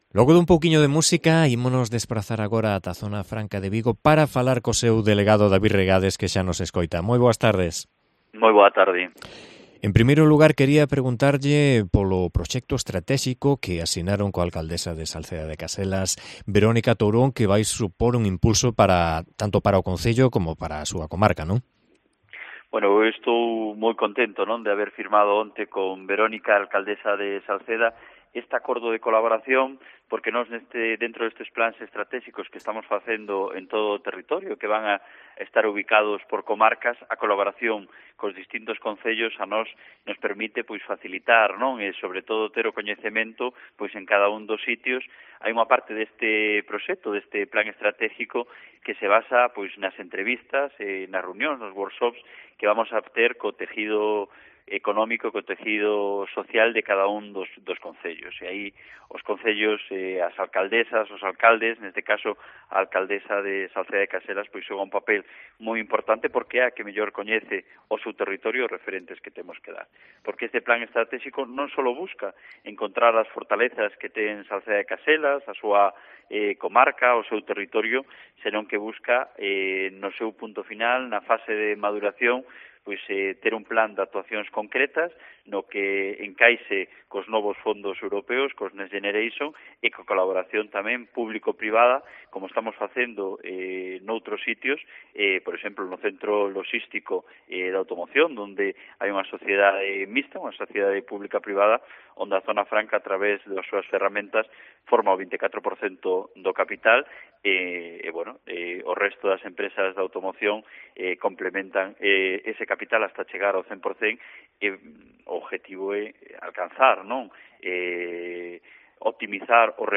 Entrevista con David Regades, delegado de Zona Franca de Vigo